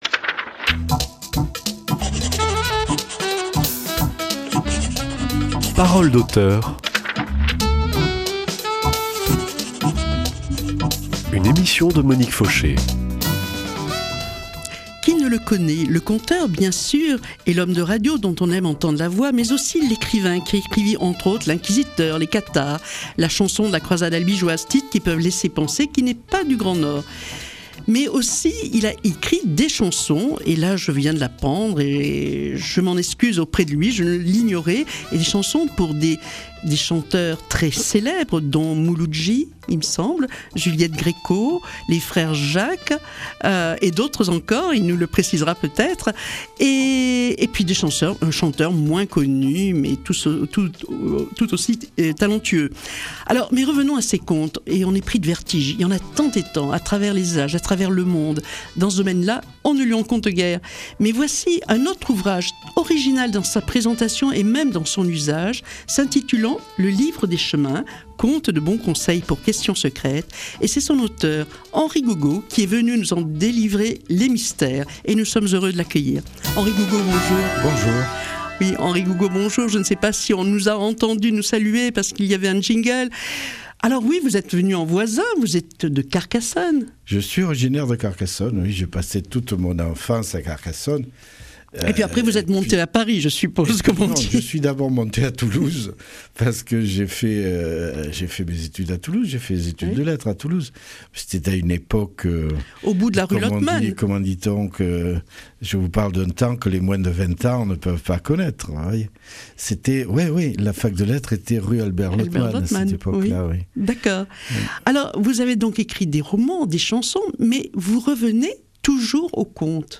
Cette semaine RADIO PRESENCE rend hommage à Henri Gougaud qui avait été l’invité